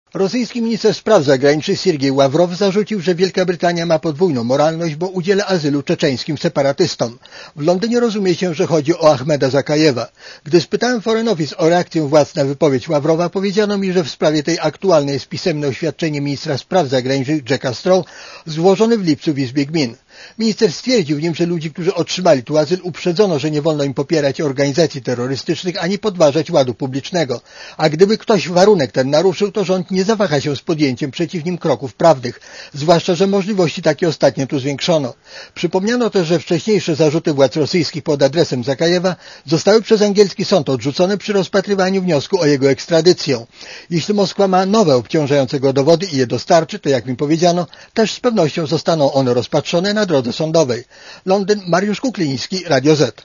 Źródło zdjęć: © RadioZet 09.09.2004 22:37 ZAPISZ UDOSTĘPNIJ SKOMENTUJ Korespondencja z Londynu